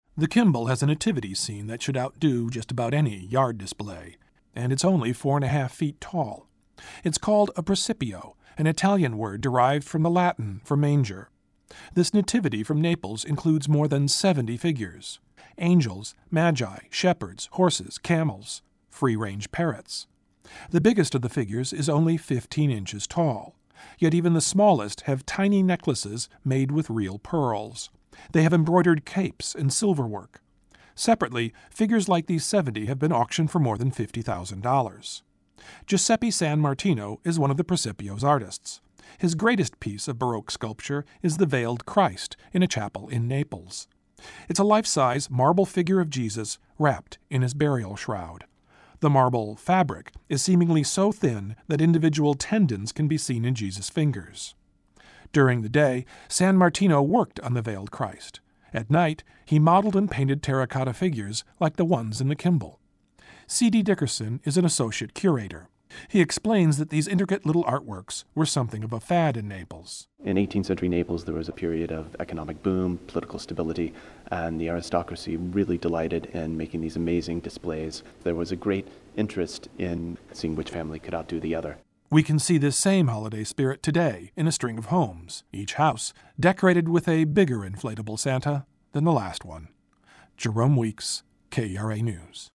• KERA radio story: